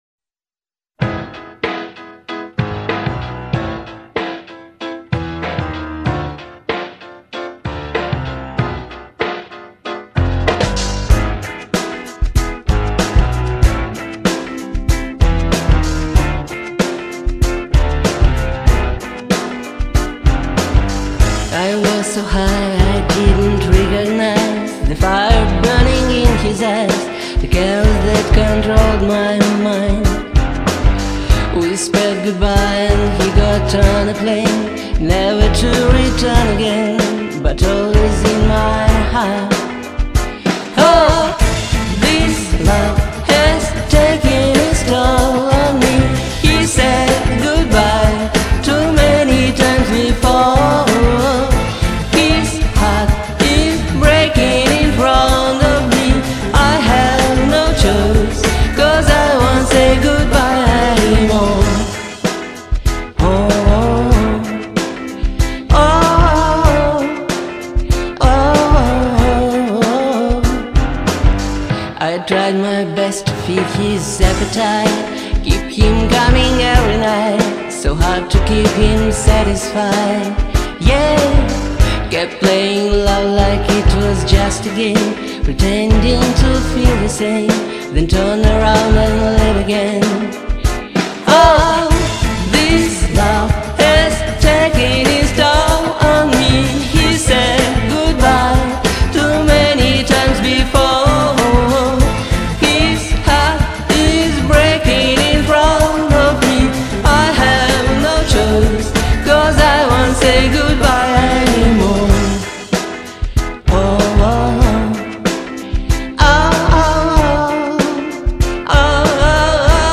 спел и сыграл классно, ритм офигенный